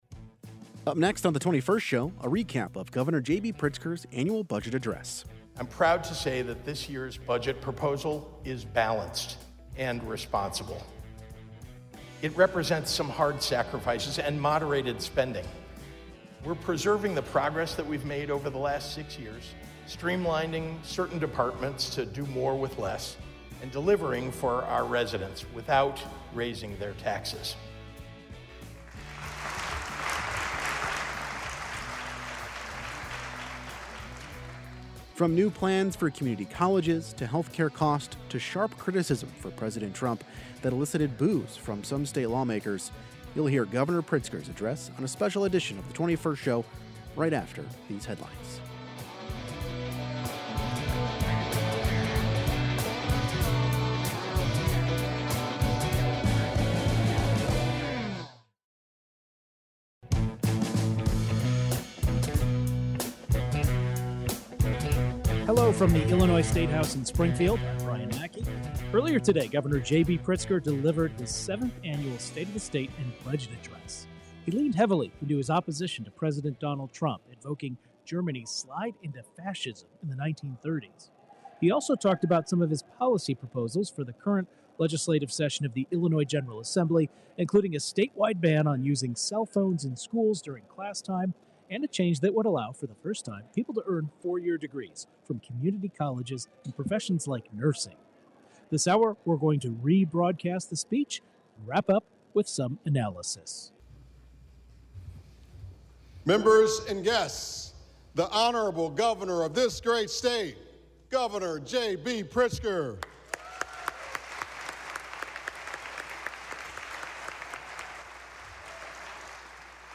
Governor JB Pritzker has delivered his seventh State of the State address touching on new plans for colleges to healthcare costs to sharp criticism for President trump that elicited boos from some state lawmakers. Two policy experts offer reactions and analysis of the speech.